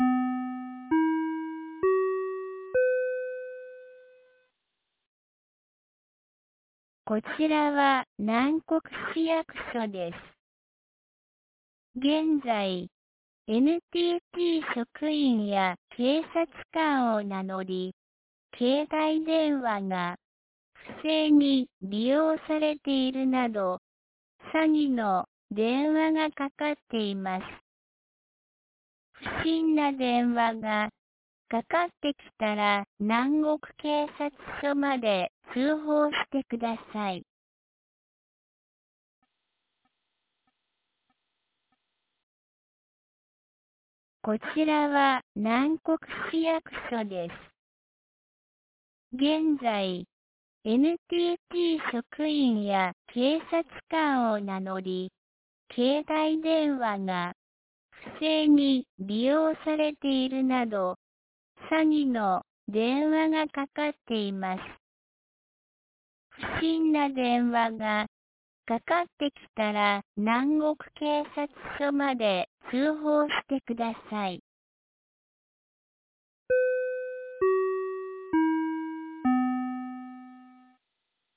2026年03月05日 15時01分に、南国市より放送がありました。